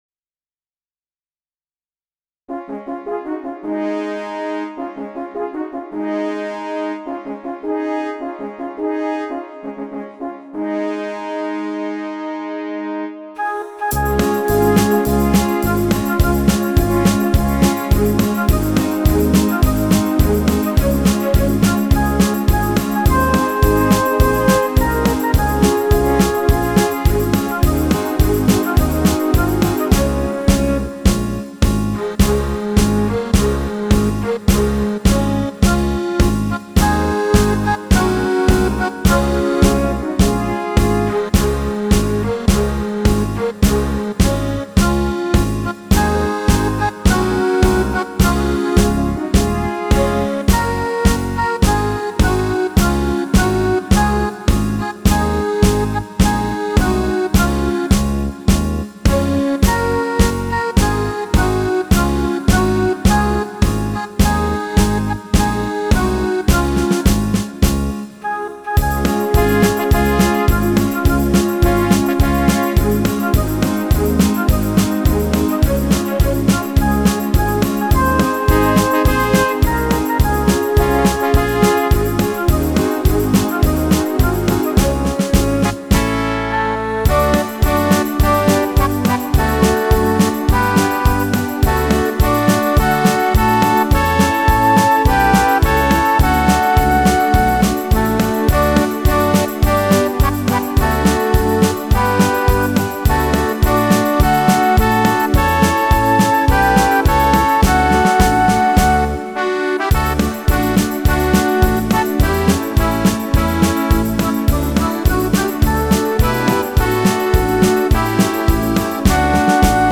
PRO MIDI INSTRUMENTAL VERSION